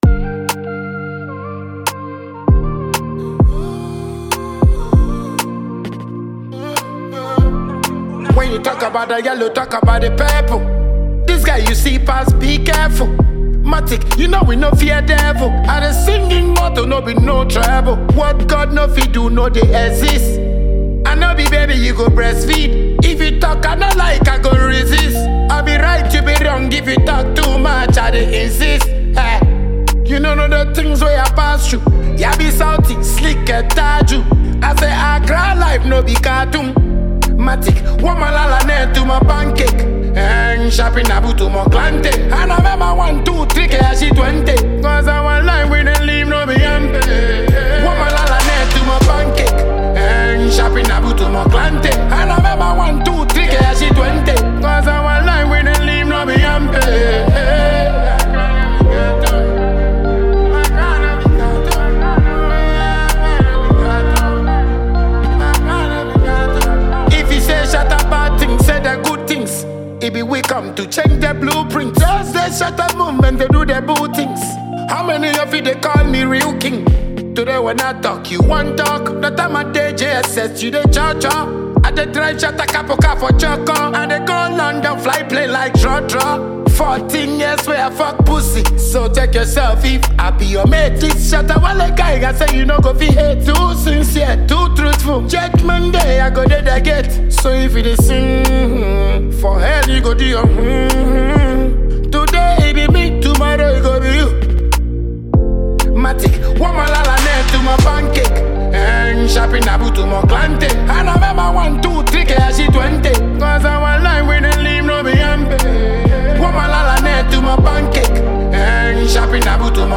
New tune from Ghanaian Dancehall musician